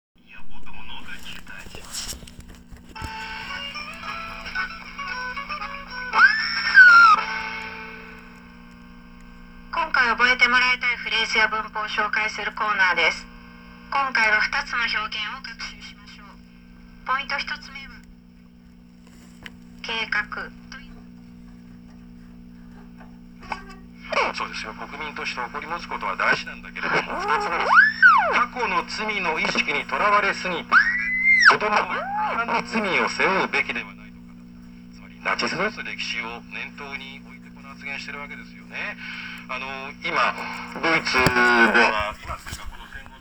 2025年1月27日、見事に鳴りました！